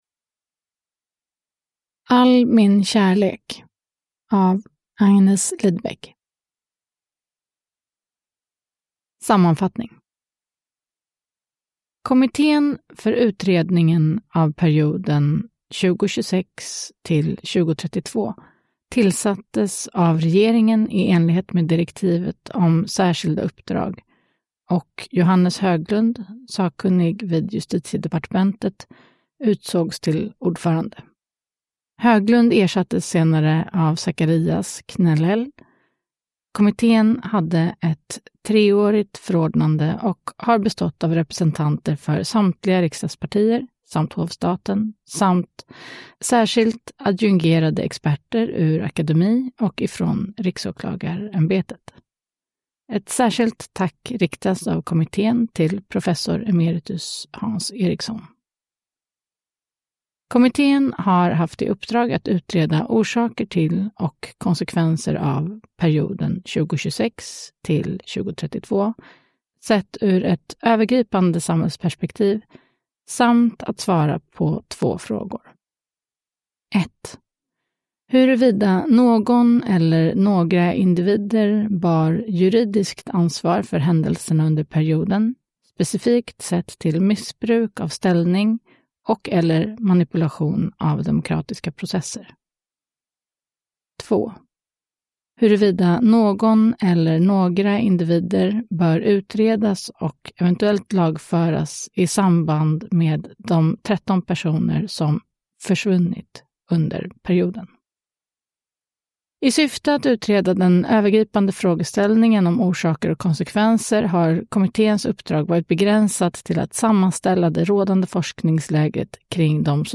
All min kärlek – Ljudbok – Laddas ner